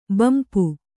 ♪ bampu